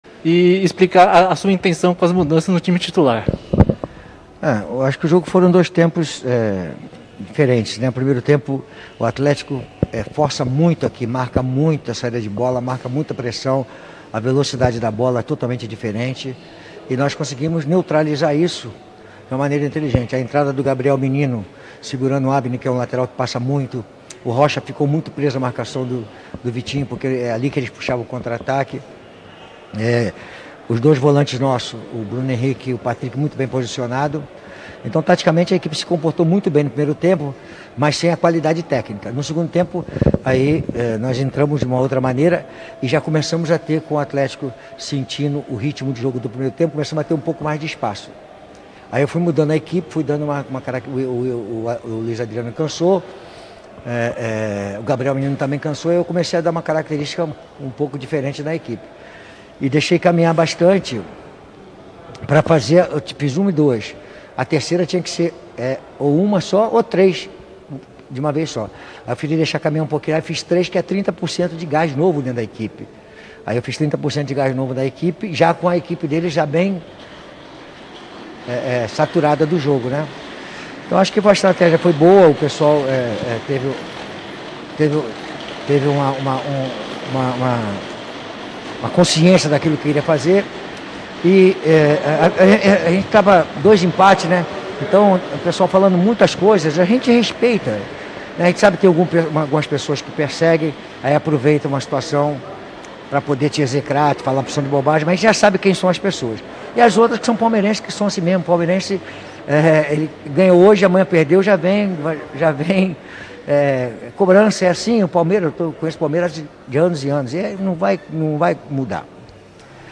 COLETIVA-I-LUXEMBURGO-I-Athletico-PR-0-x-1-Palmeiras.mp3